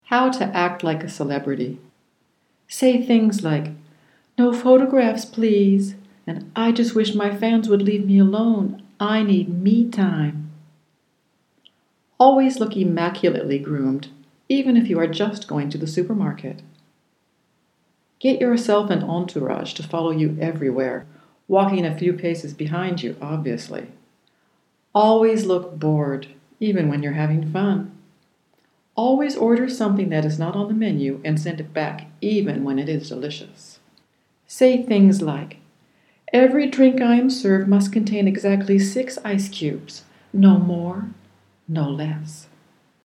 English voice demo